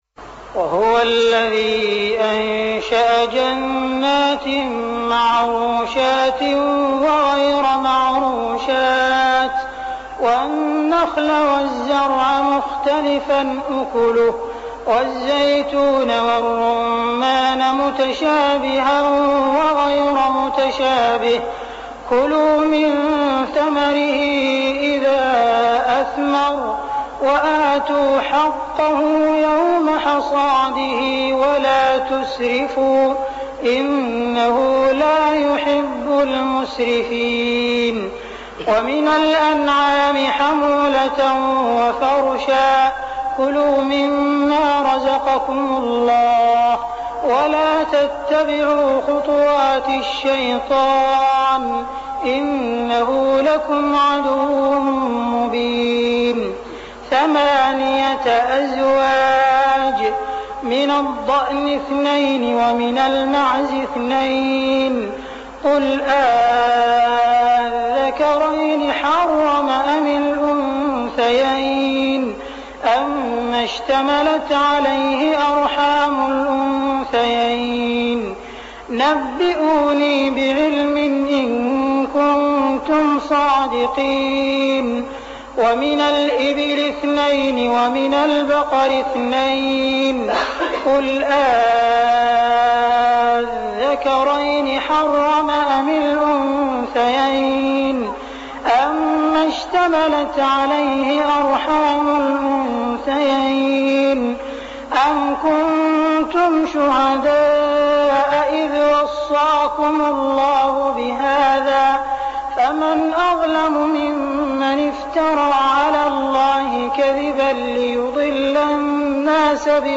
صلاة التراويح ليلة 9-9-1407هـ سورتي الأنعام 141-165 و الأعراف 1-58 | Tarawih Prayer Surah Al-An'am and Al-A'raf > تراويح الحرم المكي عام 1407 🕋 > التراويح - تلاوات الحرمين